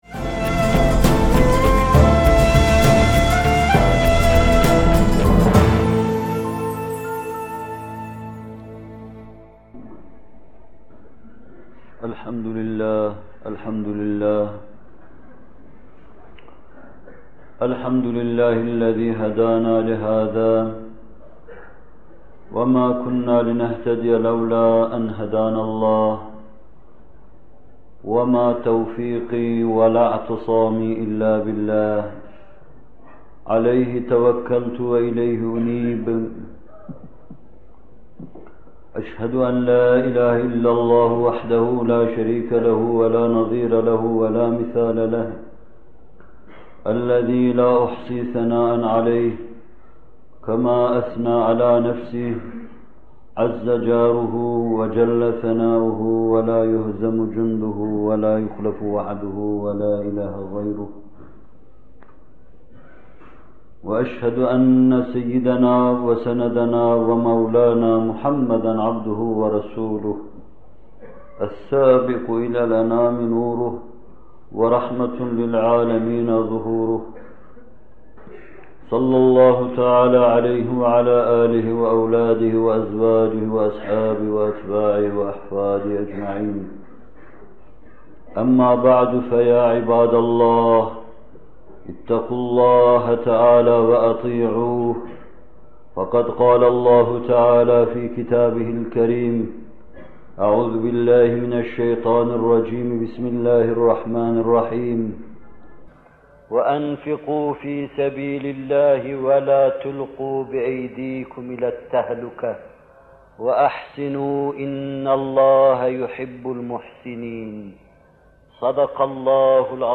Zekat Vaazları – Zekât Hutbe -5- (29.Bölüm)